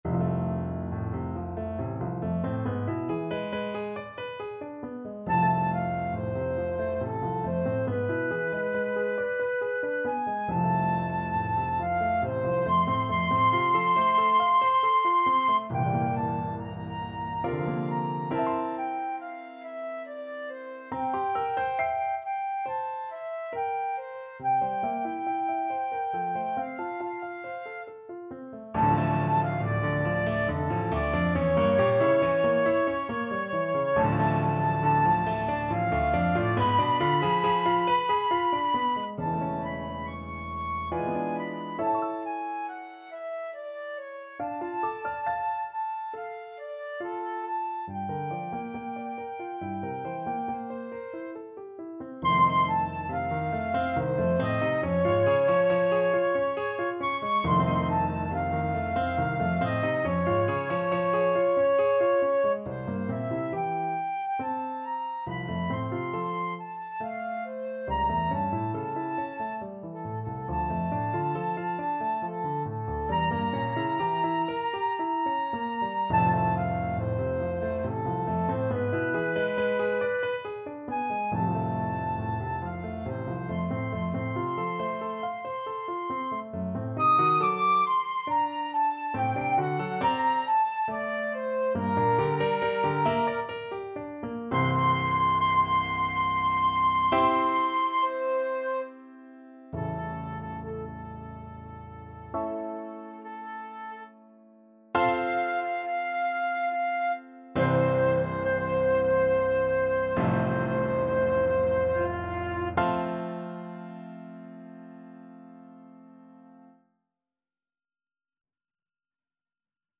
Flute
F major (Sounding Pitch) (View more F major Music for Flute )
3/4 (View more 3/4 Music)
~ = 69 Large, soutenu
Classical (View more Classical Flute Music)